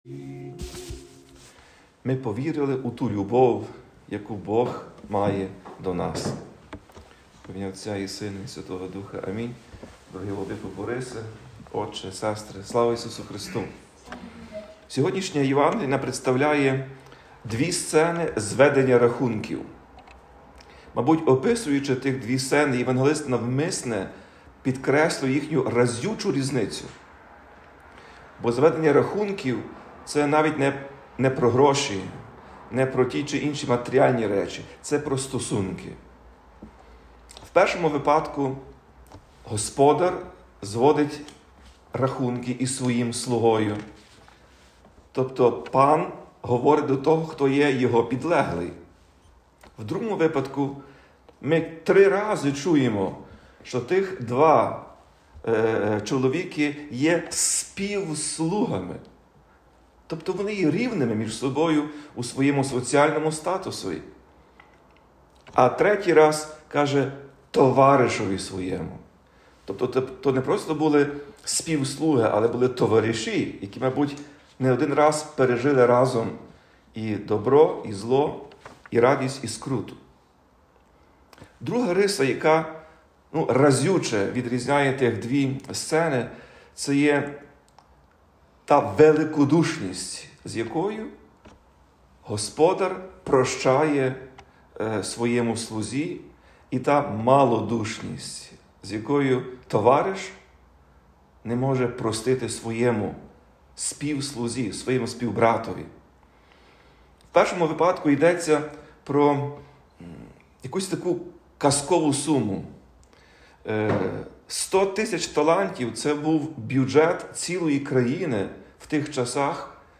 Про це сказав Отець і Глава УГКЦ Блаженніший Святослав під час проповіді в 11-ту неділю після Зіслання Святого Духа, у День Незалежності України.
Проповідь Блаженнішого Святослава